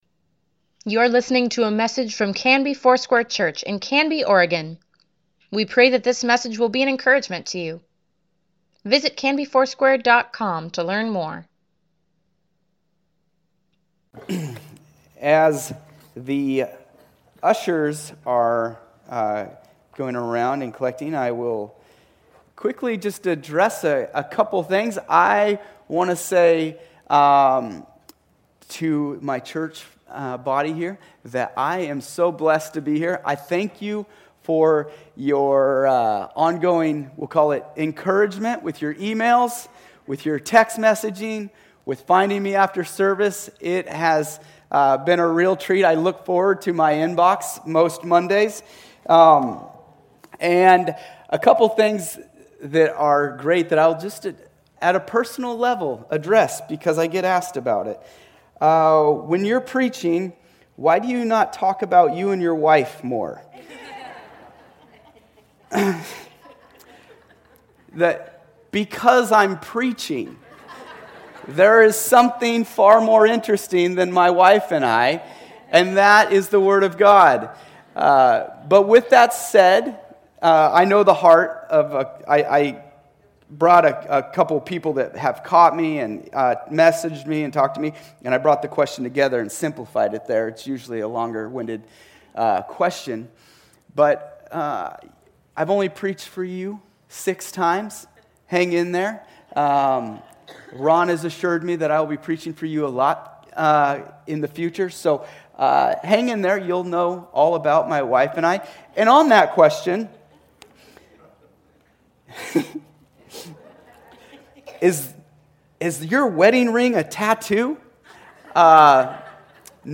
Weekly Email Water Baptism Prayer Events Sermons Give Care for Carus Testing the Spirits December 29, 2019